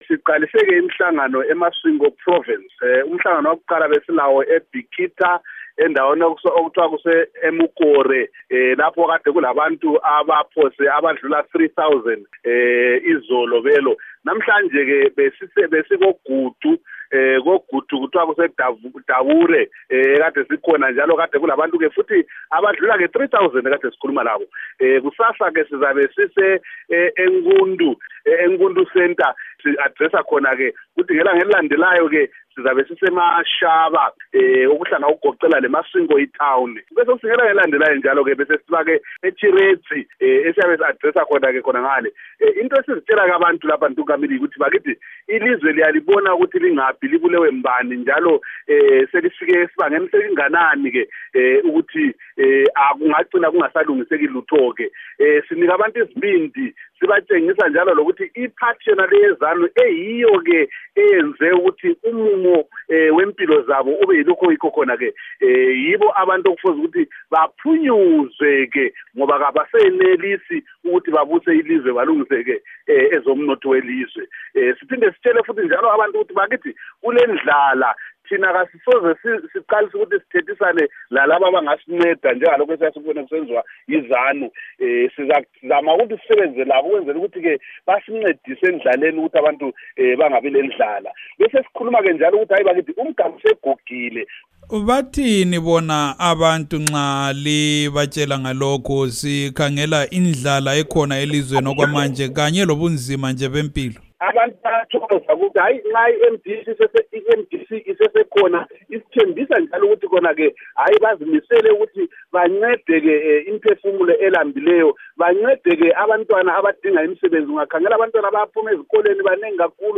Ingxoxo loMnu Abednico Bhebhe